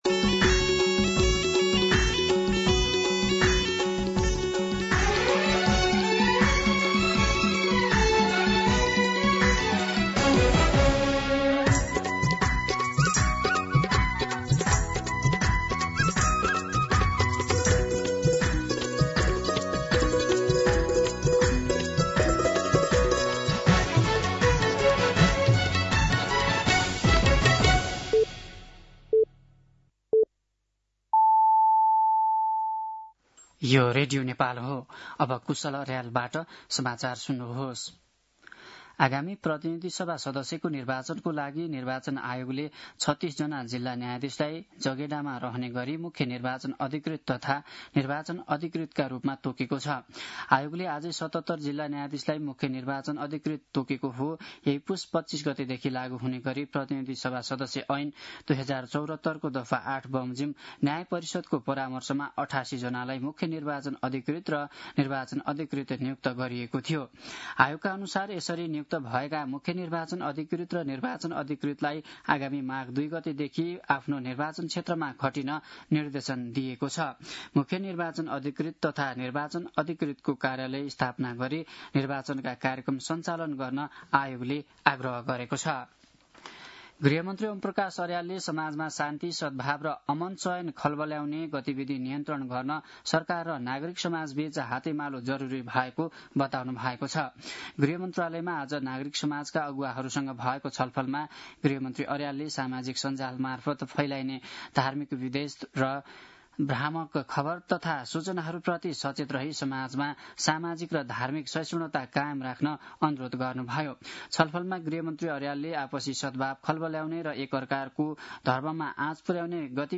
दिउँसो ४ बजेको नेपाली समाचार : २१ पुष , २०८२